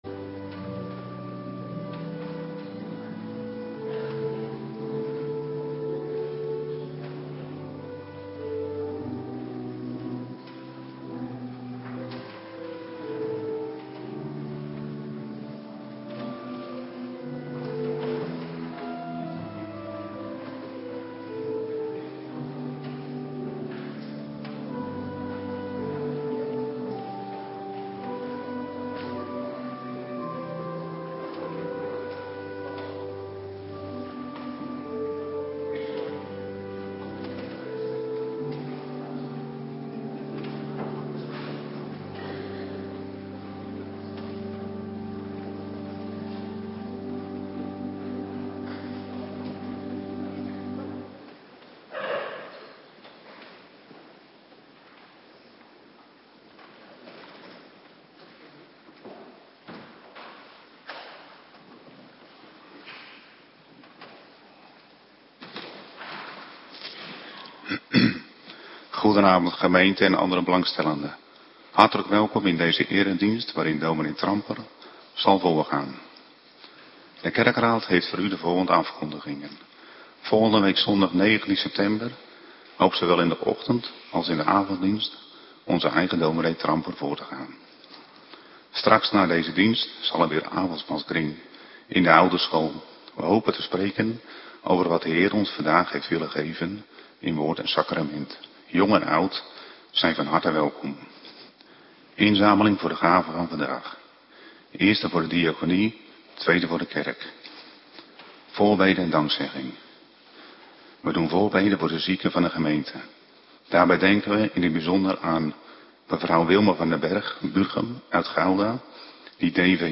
Avonddienst nabetrachting Heilig Avondmaal
Locatie: Hervormde Gemeente Waarder